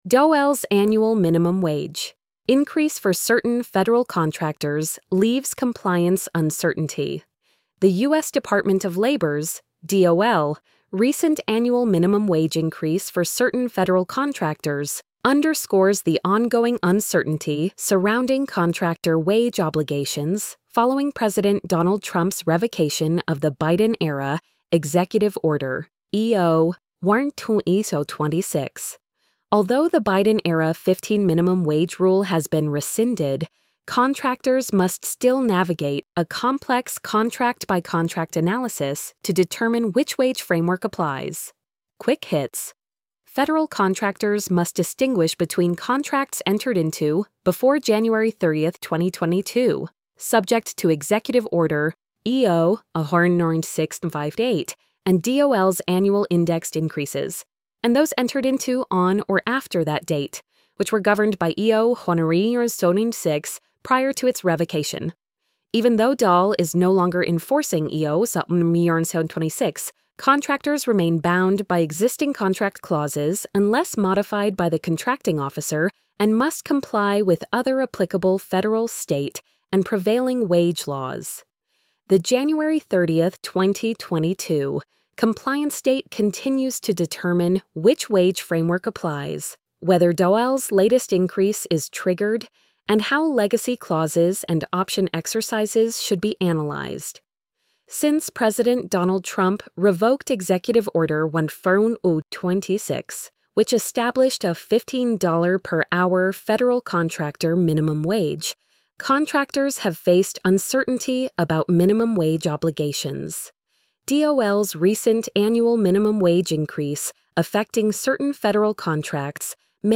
post-85520-tts.mp3